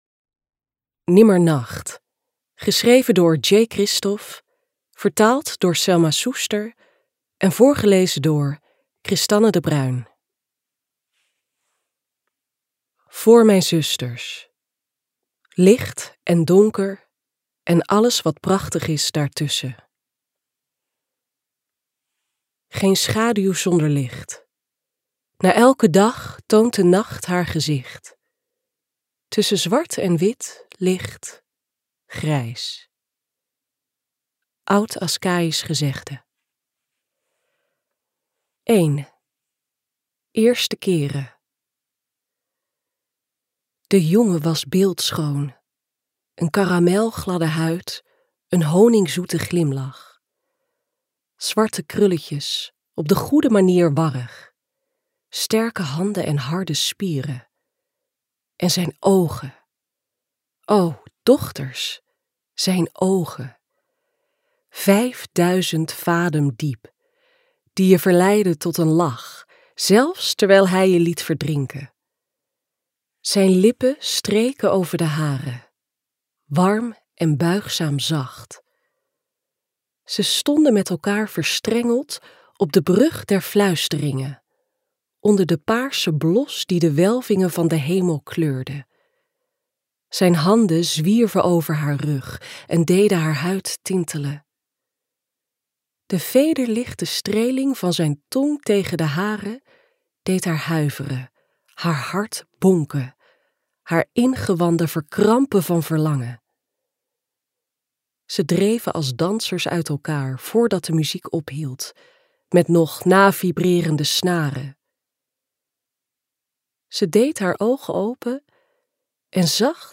Gratis leesfragment